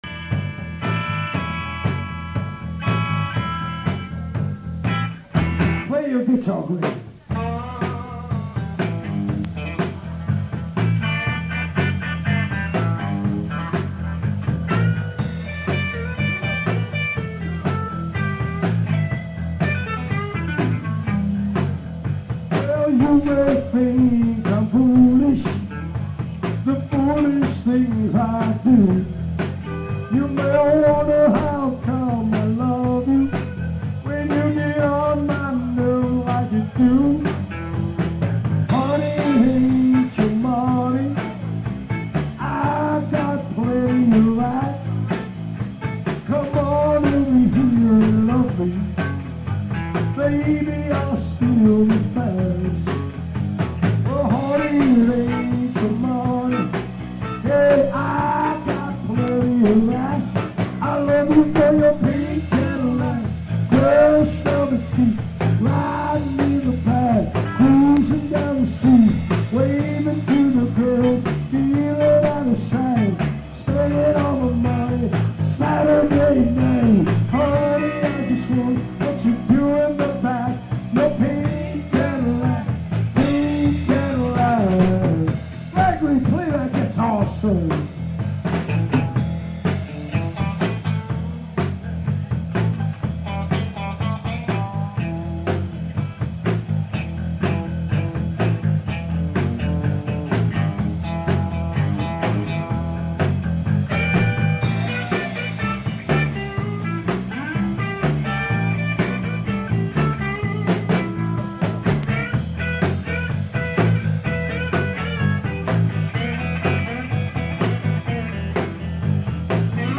Recorded Live.